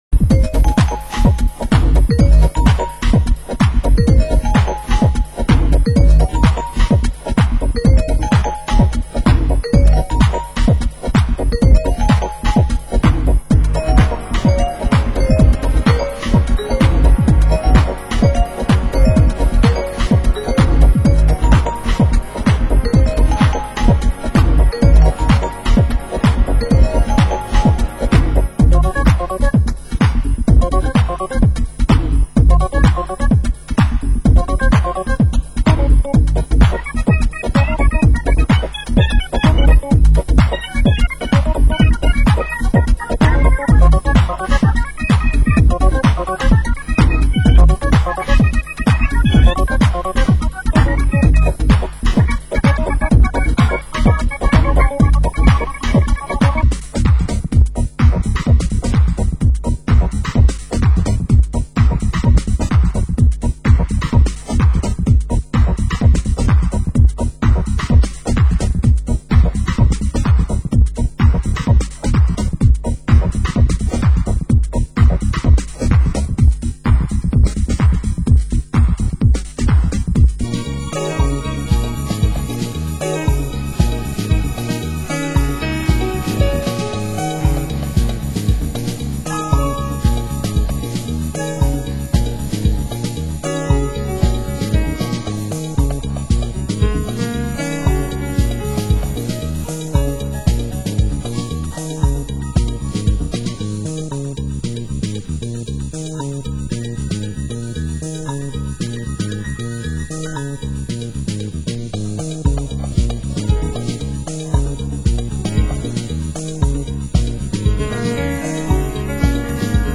Genre: UK House
Keyboards
Bass